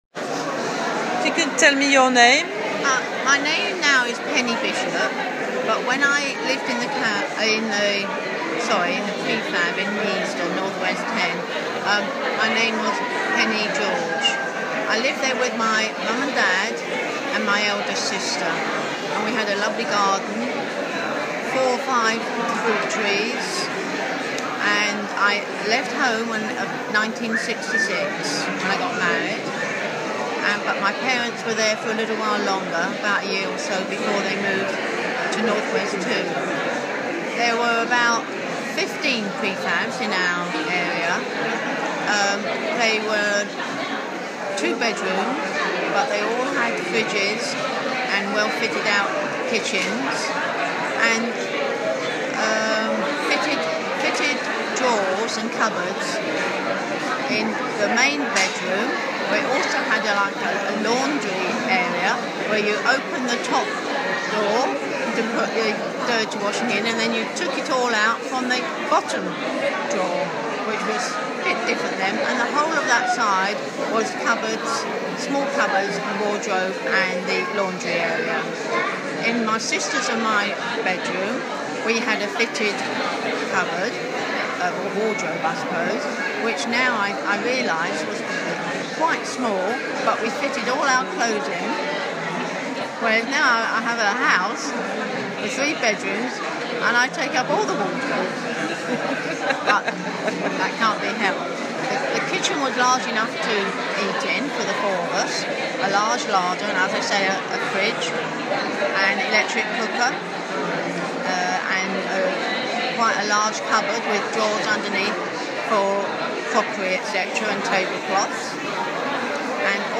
Moving Prefab Museum Event - East End Canal Festival
Interview